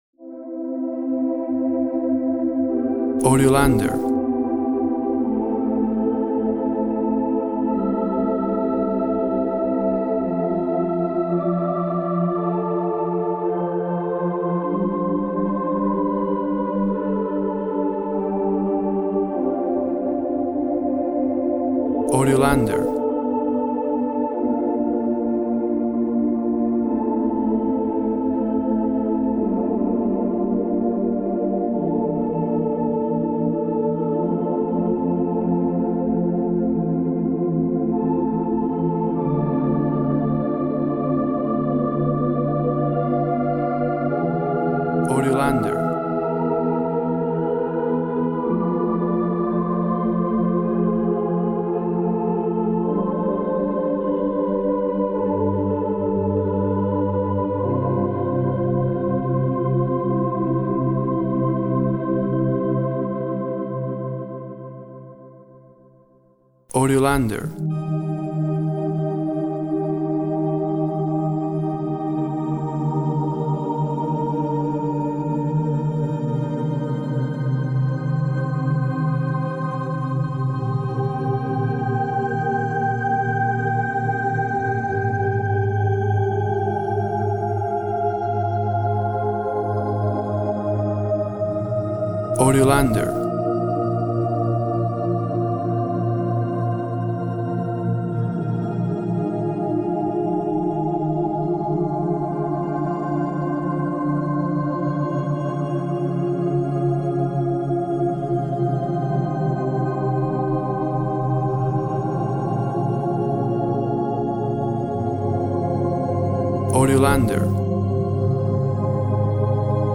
Lush synth music of the night.